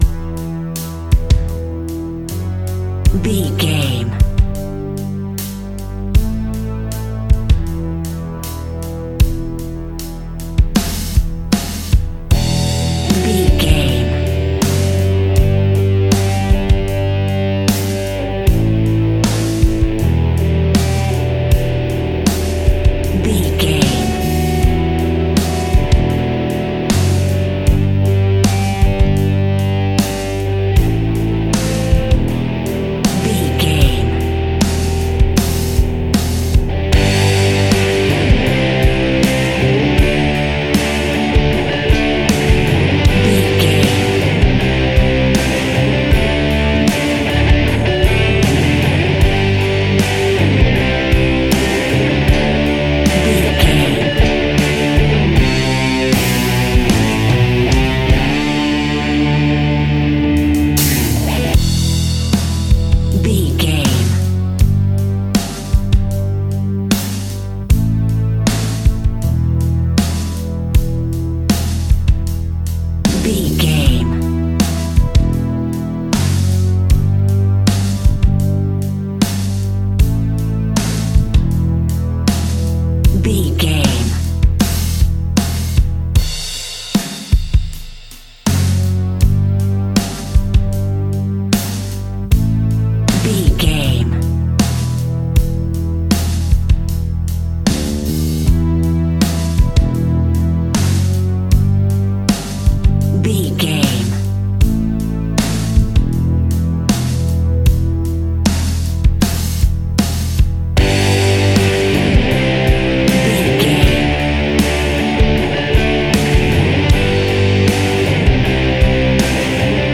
Epic / Action
Aeolian/Minor
hard rock
blues rock
rock instrumentals
Rock Bass
heavy drums
distorted guitars
hammond organ